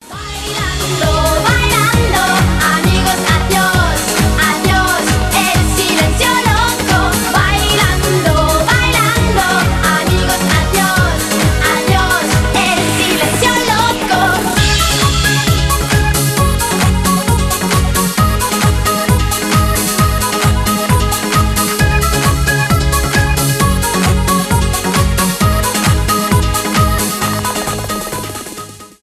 евродэнс
euro house
зажигательные , танцевальные